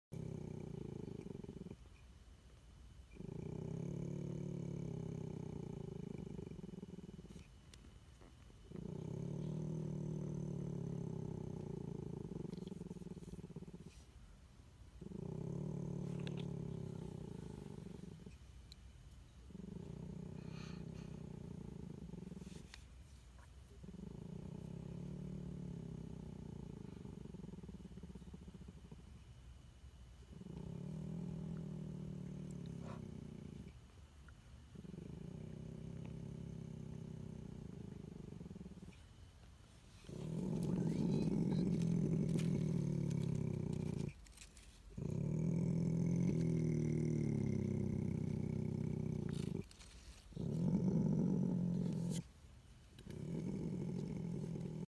Another variation on the possum growl.
• Category: Sounds of possum